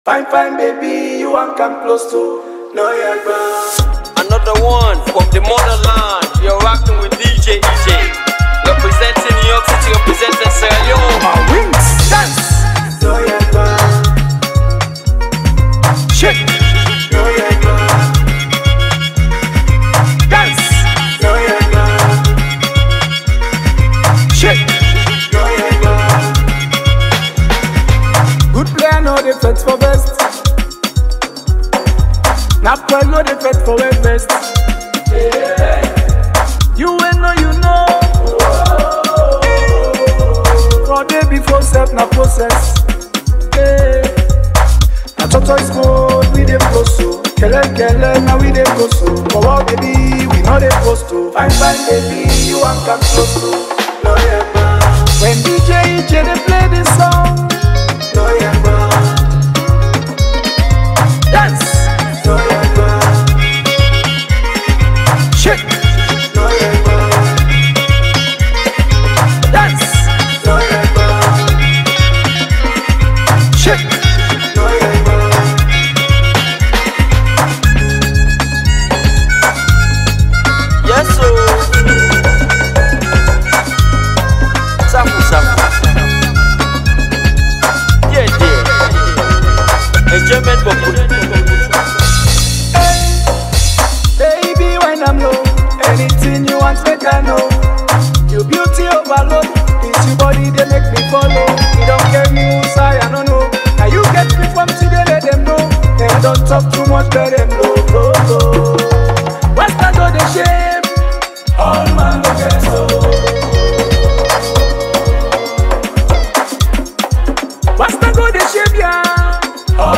make the song danceable at all levels